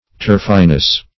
Turfiness \Turf"i*ness\, n. Quality or state of being turfy.
turfiness.mp3